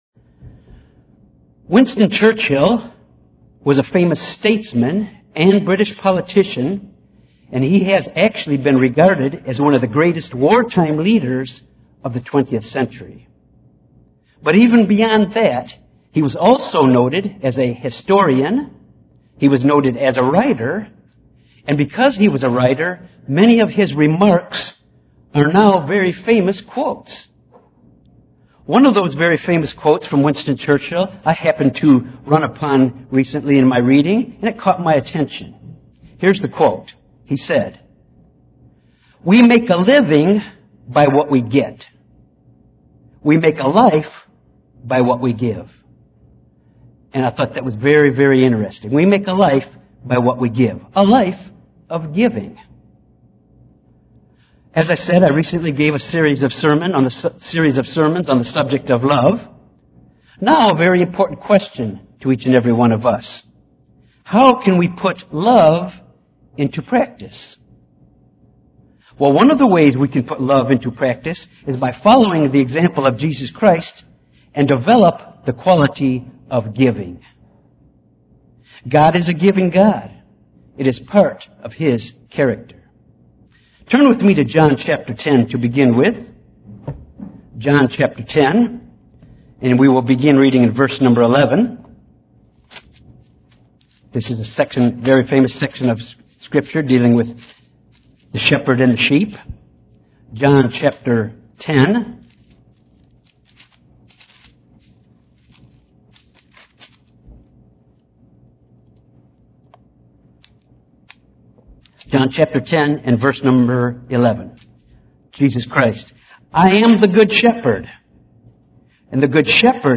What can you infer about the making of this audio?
Given in Little Rock, AR Jonesboro, AR